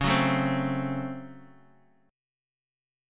DbM7sus4#5 chord